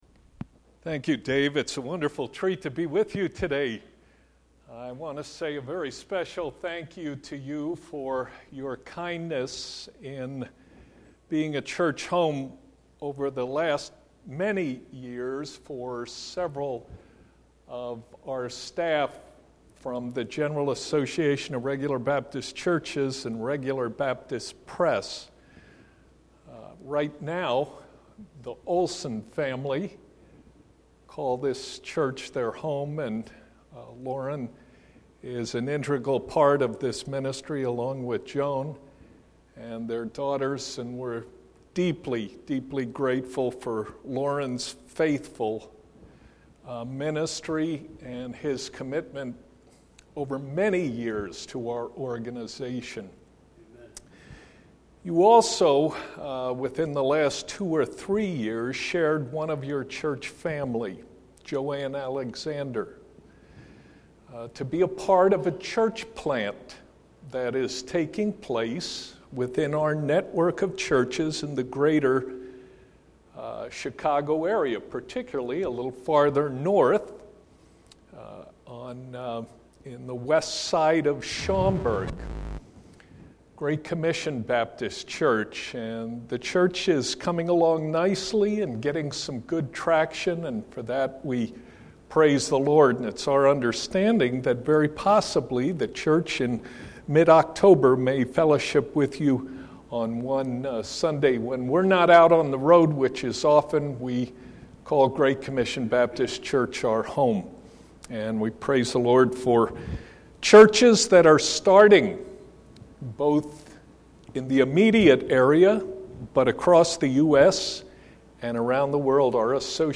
Sermons Archive - Grace Community Church Lombard